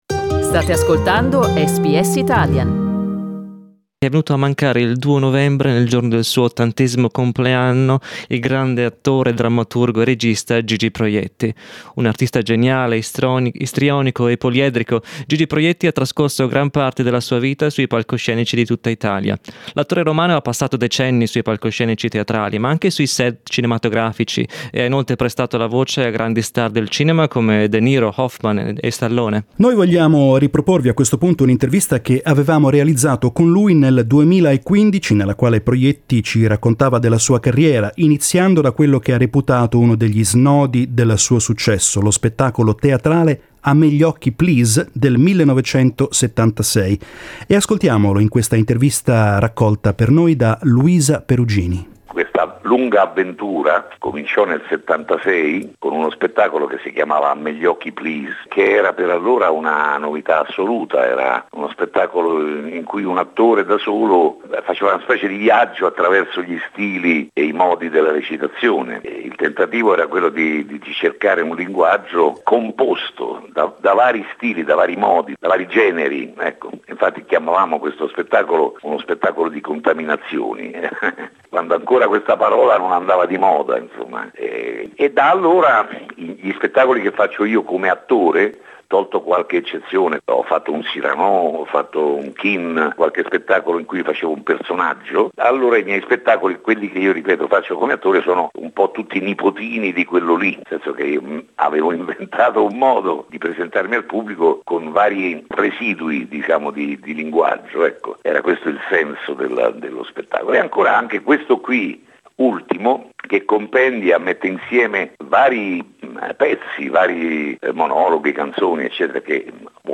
Noi vi riproponiamo un'intervista che avevamo realizzato con lui nel 2016, durante la quale Proietti ci raccontò della sua carriera, iniziando da quello che riteneva essere stato uno degli snodi del suo successo, lo spettacolo teatrale "A me gli occhi", del 1976.